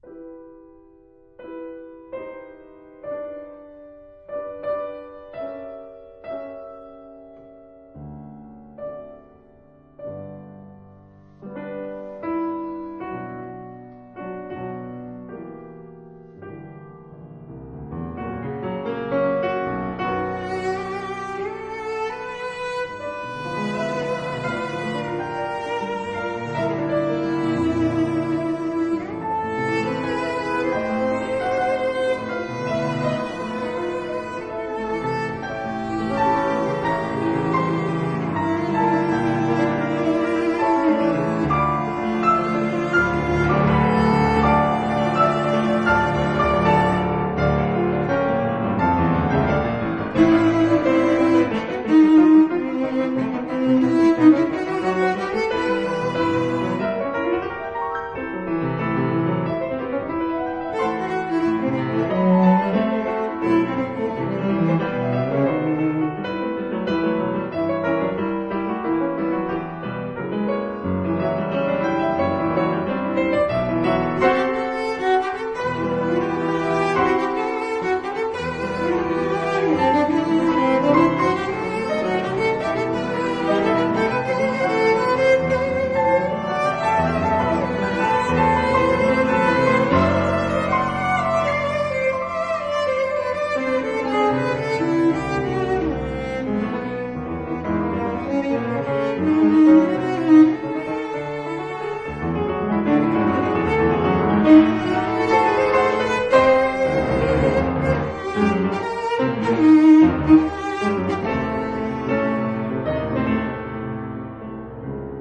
儘管年輕，但曲子中，展現了對形式與質地的掌握。